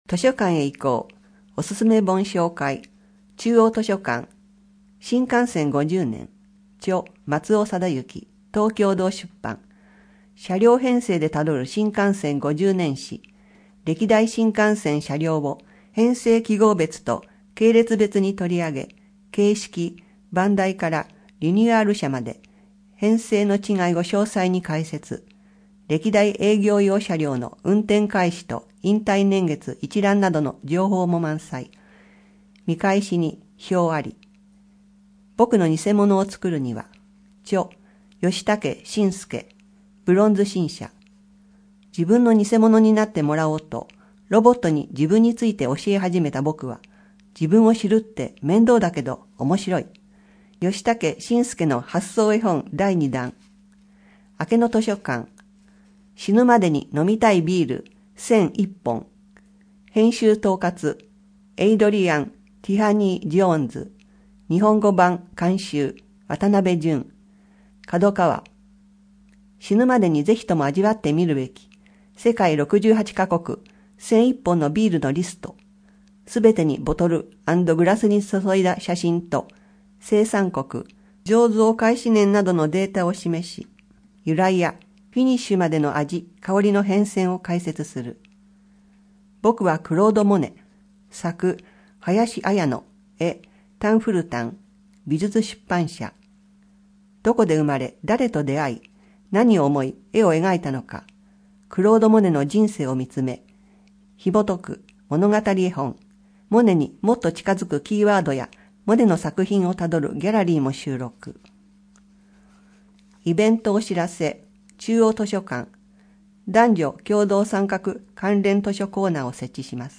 声の広報は、朗読ボランティア「野ばらの会」様のご協力により、目の不自由な人や高齢者など、広報紙を読むことが困難な人のために「声の広報筑西People」としてお届けしています。